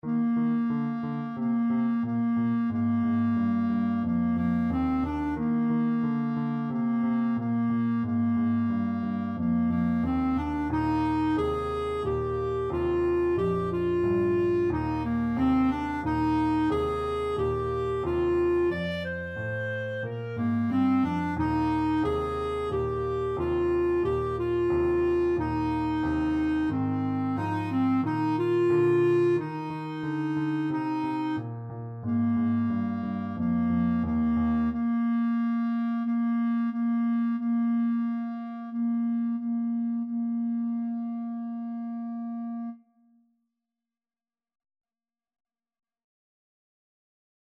A beginners piece with a rock-like descending bass line.
March-like = 90
4/4 (View more 4/4 Music)
Arrangement for Clarinet and Piano
Pop (View more Pop Clarinet Music)